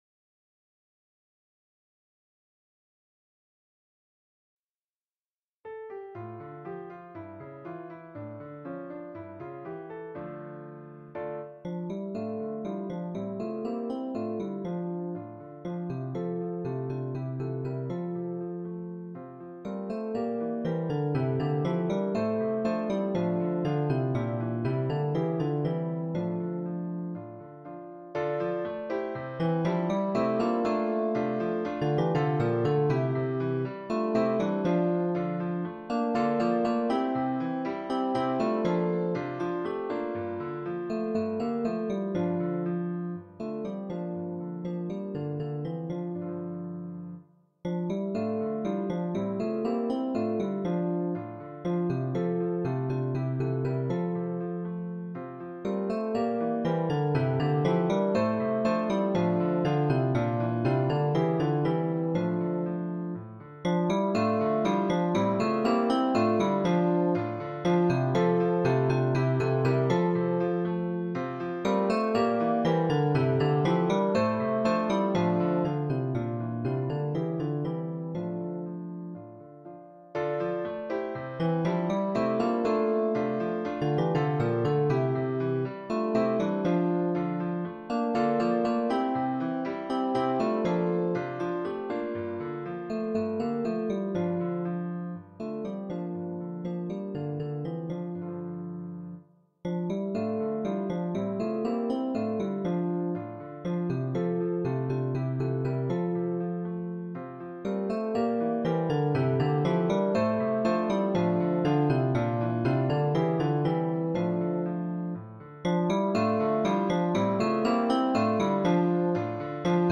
MP3 version instrumentale
Alto